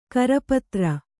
♪ karapatra